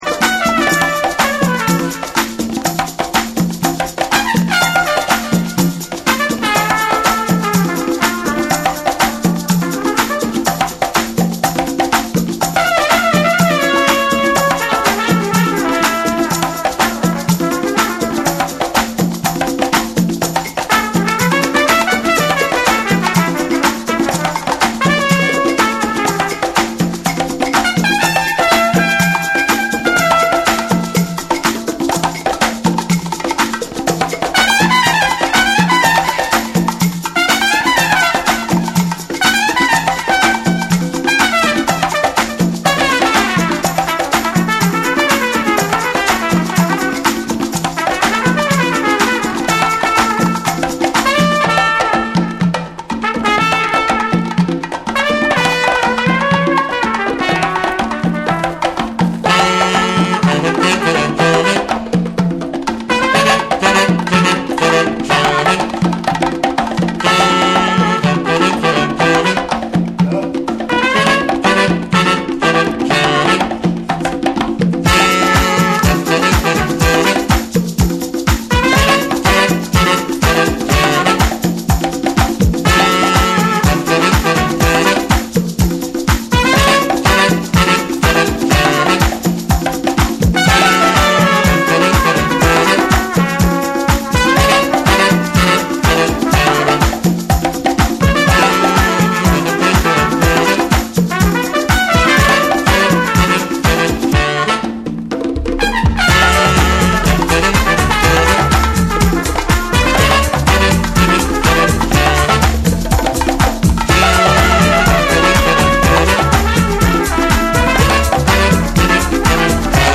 アフロなパーカッションを軸に、程よくトロピカルなホーンのフレーズが心地良く絡む
TECHNO & HOUSE / ORGANIC GROOVE